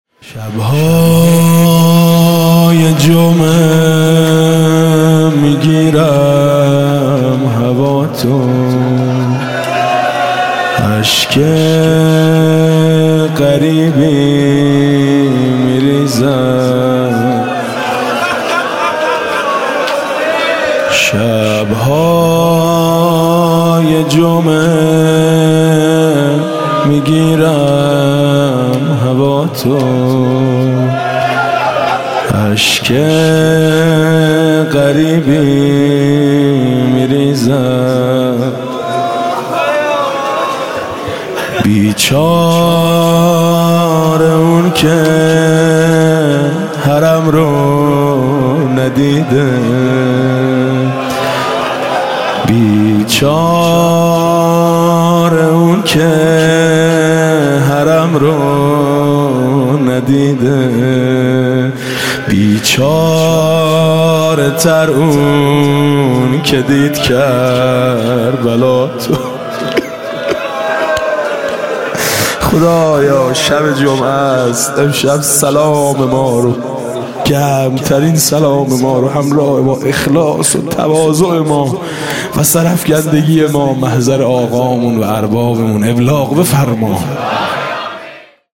«محرم 1396» (شب اول) روضه پایانی: شب های جمعه میگیرم هواتو